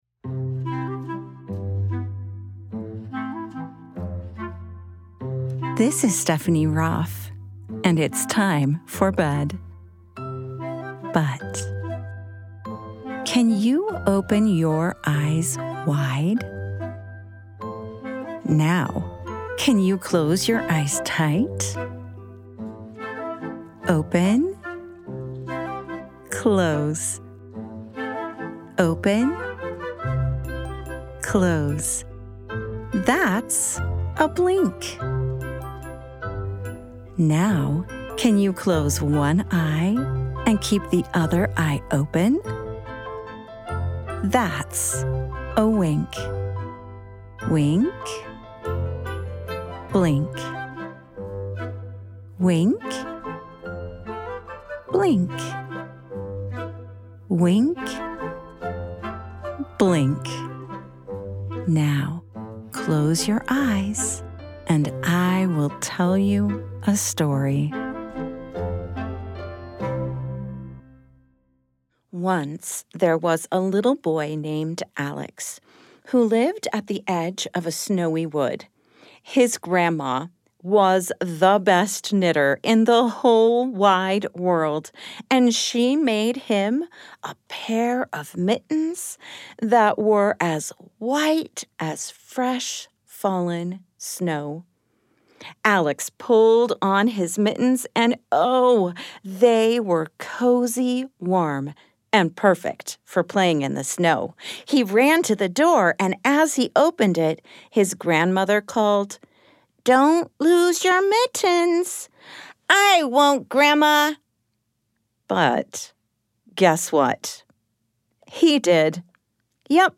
The Mitten: A Mindful Bedtime Story for Kids